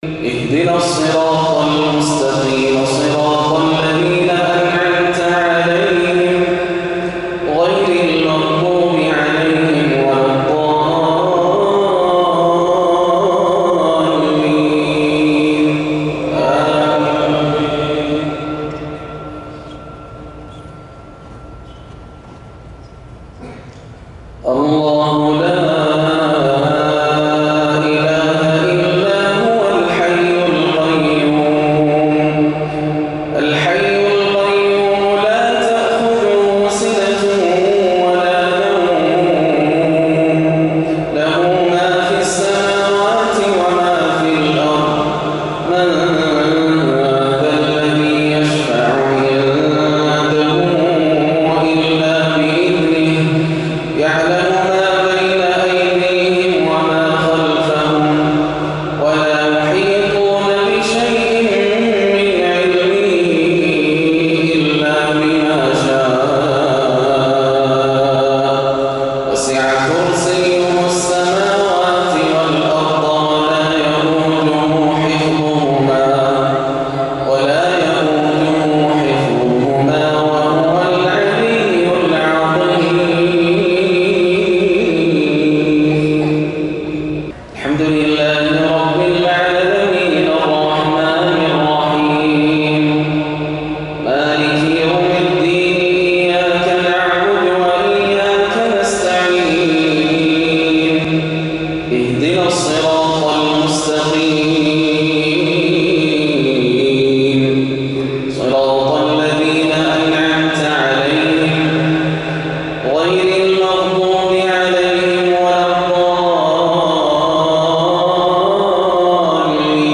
صلاة المغرب 4-1-1437هـ من سورتي البقرة 255 و النور 35 > عام 1437 > الفروض - تلاوات ياسر الدوسري